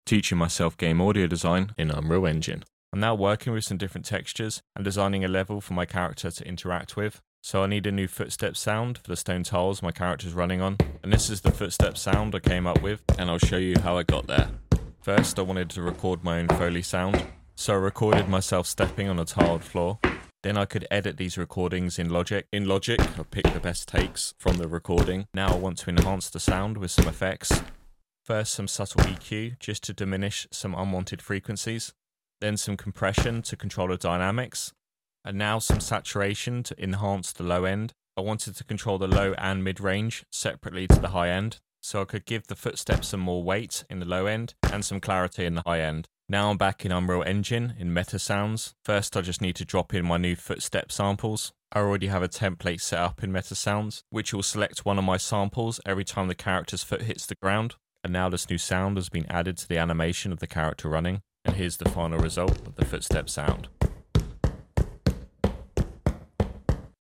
# 7 Teaching myself game audio design in Unreal Engine. Stone Footstep Sound.